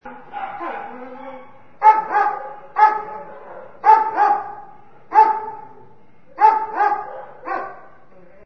descargar sonido mp3 perrera